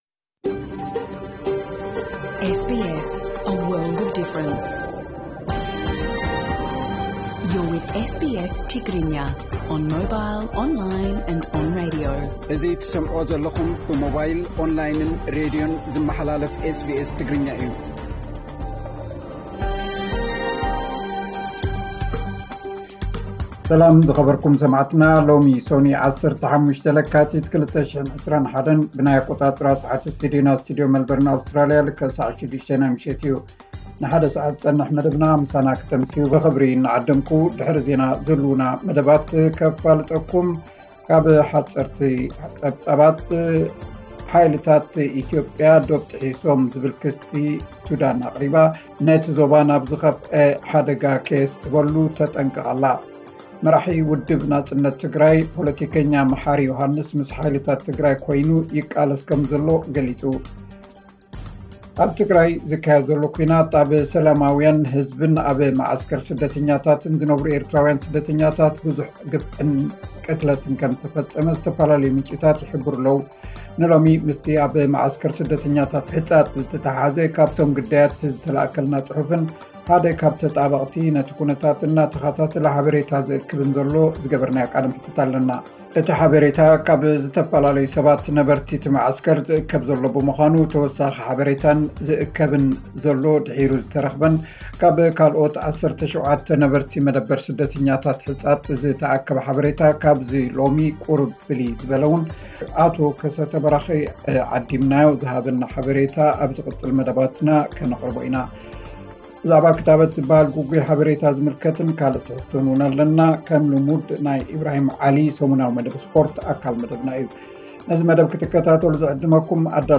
ዕለታዊ ዜና SBS ትግርኛ 15 ለካቲት 2021